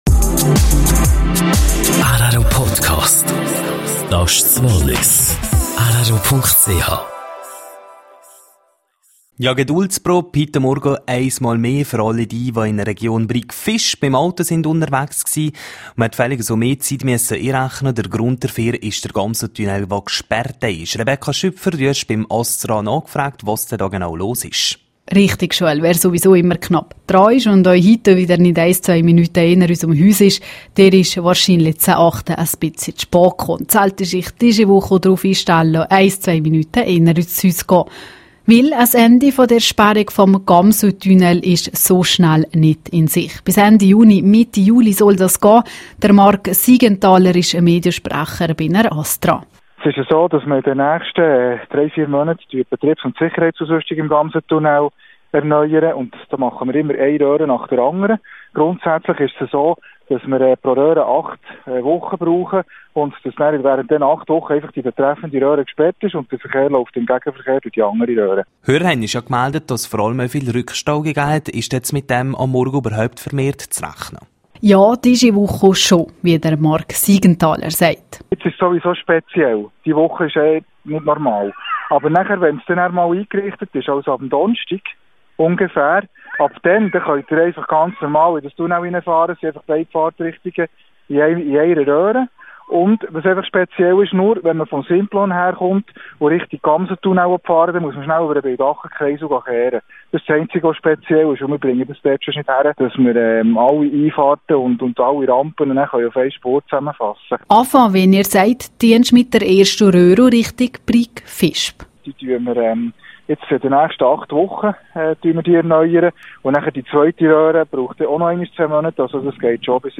12:30 Uhr Nachrichten (4.35MB)